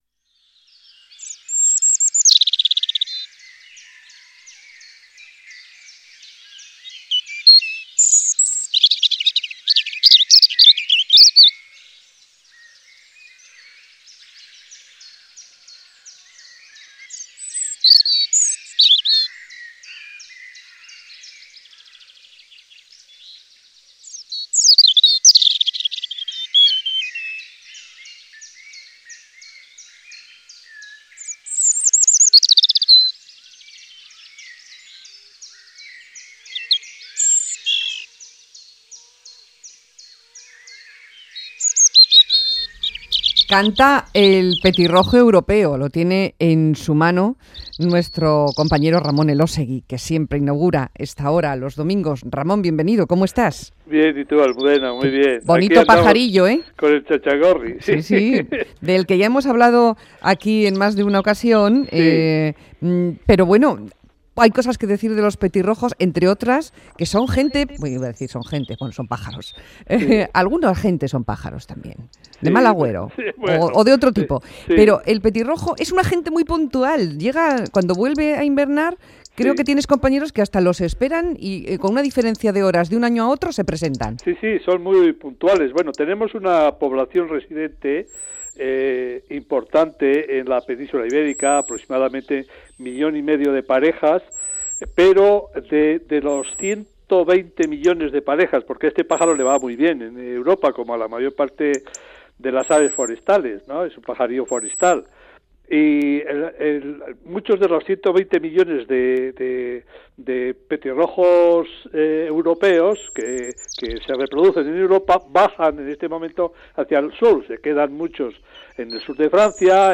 Radio Euskadi PAJAROS